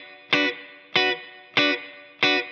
DD_TeleChop_95-Bmin.wav